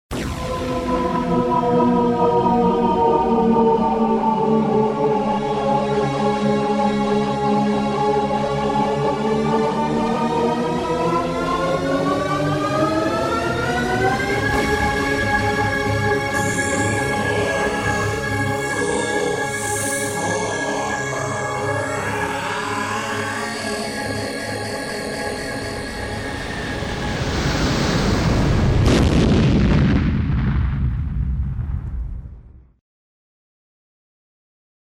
Шум полета Бабы-Яги